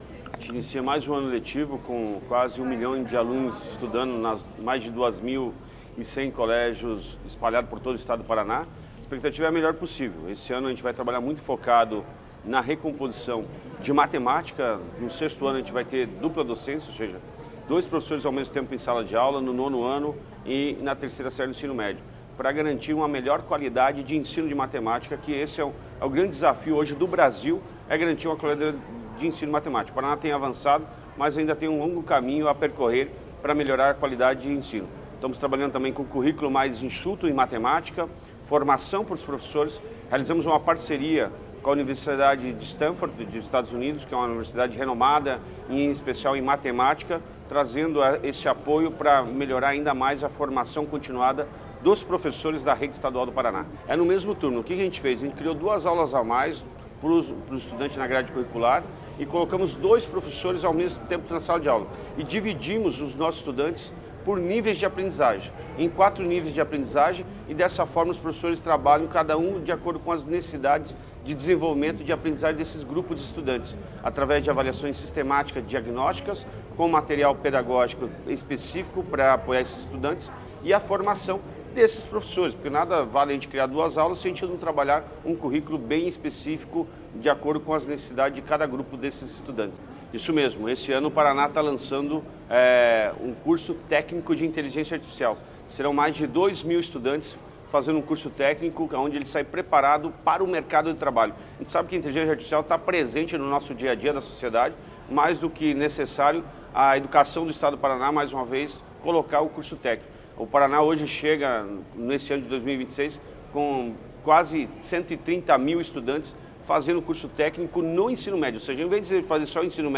Sonora do secretário da Educação, Roni Miranda, sobre o início do ano letivo de 2026 na rede estadual de ensino